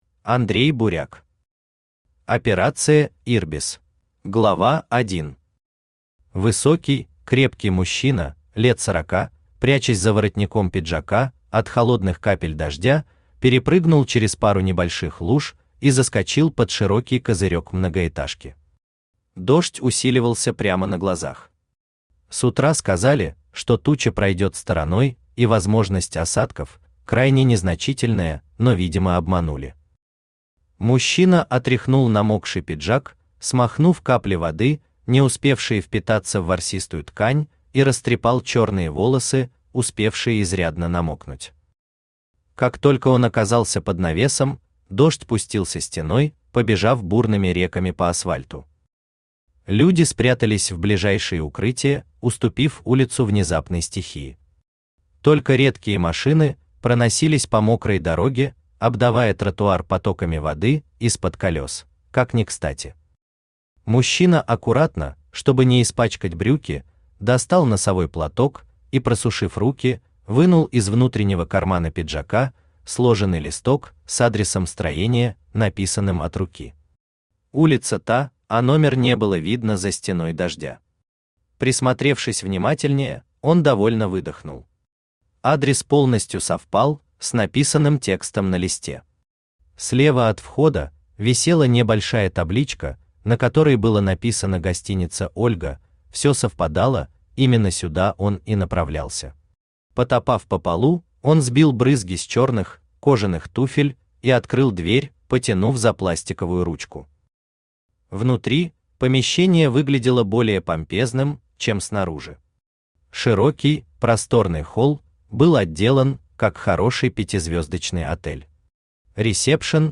Аудиокнига Операция «ИРБИС» | Библиотека аудиокниг
Aудиокнига Операция «ИРБИС» Автор Андрей Буряк Читает аудиокнигу Авточтец ЛитРес.